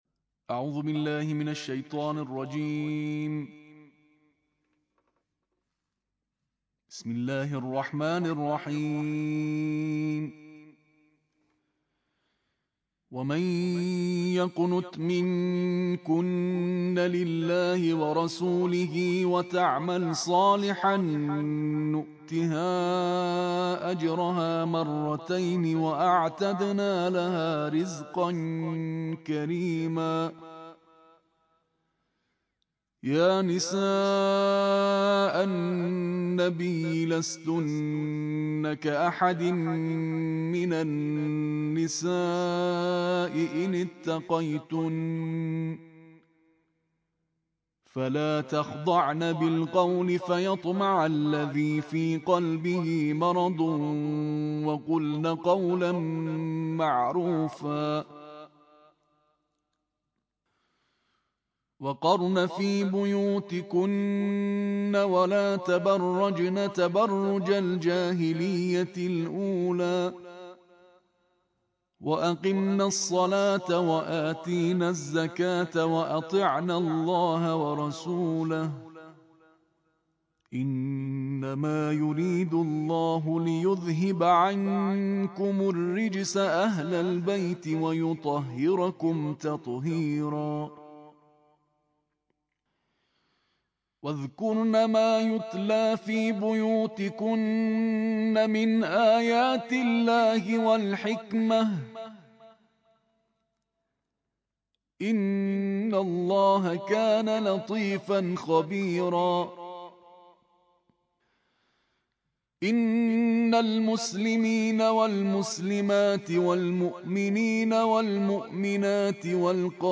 Kullum tare da kur’ani: Karatun Tarteel